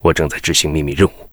文件 文件历史 文件用途 全域文件用途 Bk2_tk_03.ogg （Ogg Vorbis声音文件，长度1.3秒，122 kbps，文件大小：20 KB） 源地址:游戏语音 文件历史 点击某个日期/时间查看对应时刻的文件。